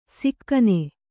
♪ sikkane